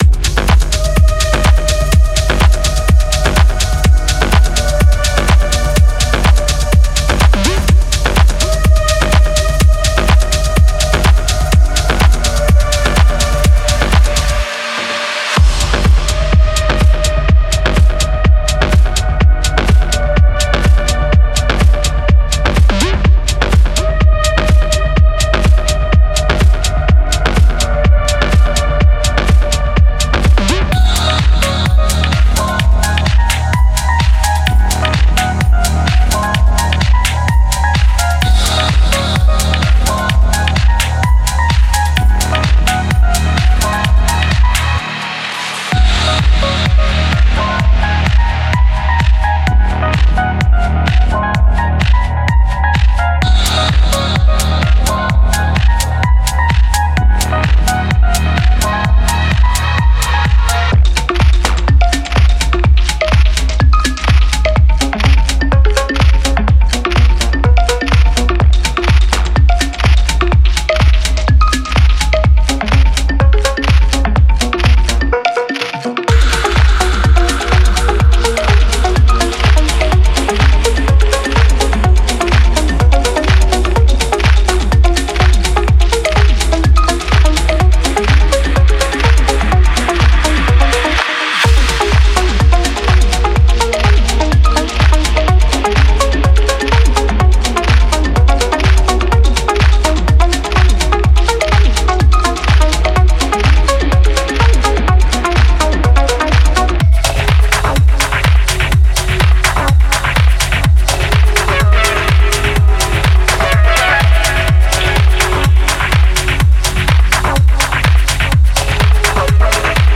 Genre:Minimal Techno
デモサウンドはコチラ↓